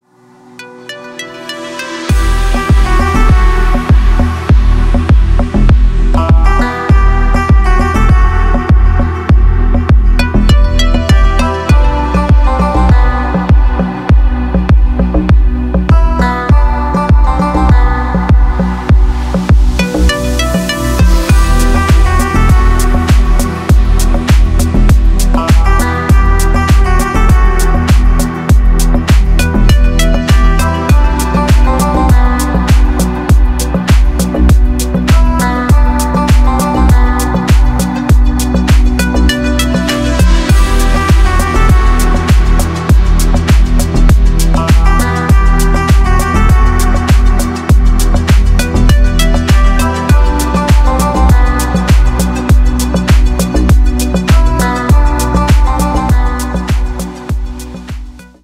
• Качество: 320, Stereo
deep house
спокойные
без слов
красивая музыка